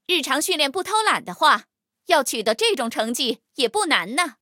黑豹MVP语音.OGG